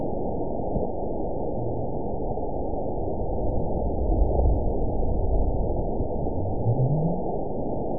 event 917173 date 03/22/23 time 23:08:20 GMT (2 years, 1 month ago) score 9.73 location TSS-AB04 detected by nrw target species NRW annotations +NRW Spectrogram: Frequency (kHz) vs. Time (s) audio not available .wav